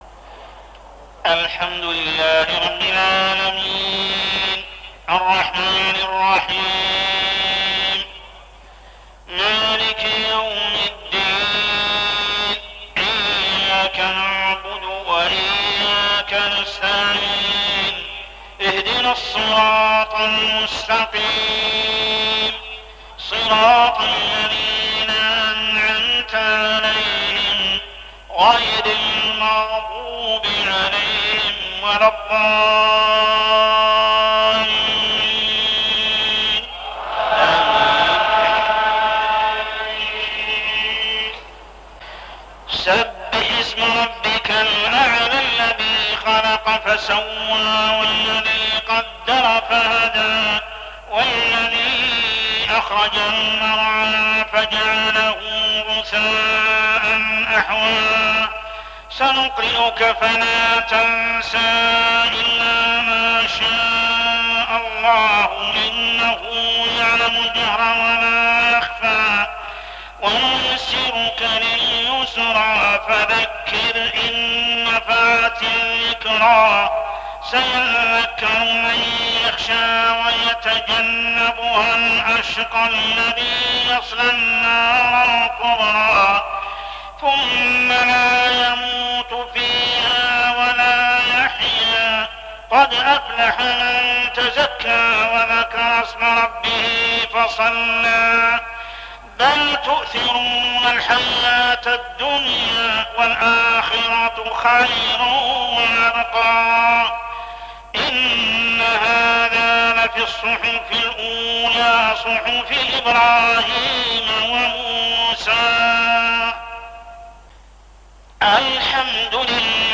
صلاة العشاء عام 1416هـ سورتي الأعلى و العاديات كاملة | Isha prayer Surah Al-A'la and Adiyat > 1416 🕋 > الفروض - تلاوات الحرمين